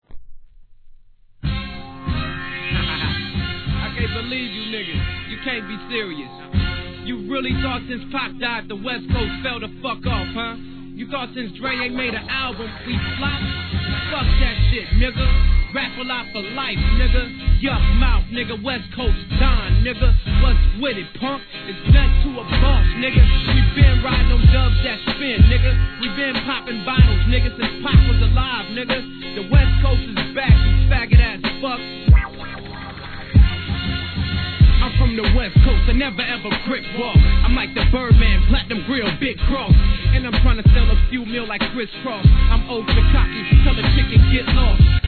G-RAP/WEST COAST/SOUTH
ウエッサイフレイバーを漂わす楽曲に、NATE DOGG的なコーラス がハマッた西物ファンなら要チェックな一枚。